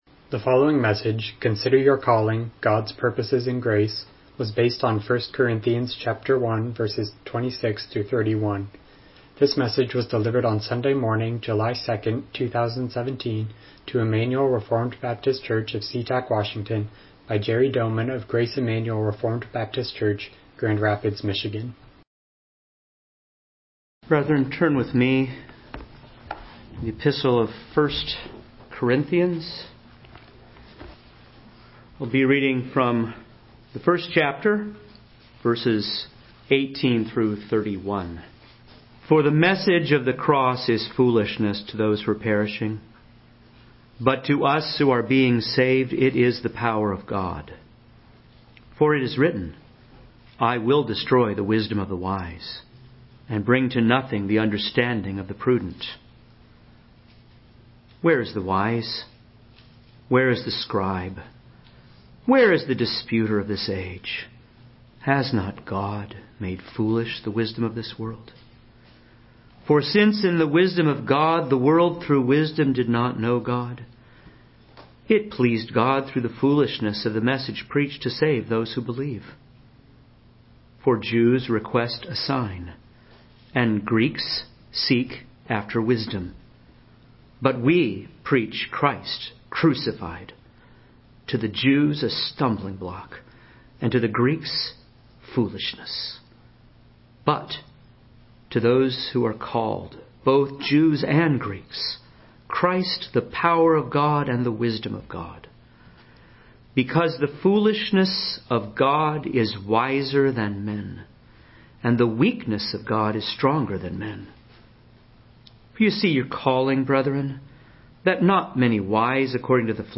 Studies in First Corinthians Passage: 1 Corinthians 1:26-31 Service Type: Morning Worship « How Do We Know?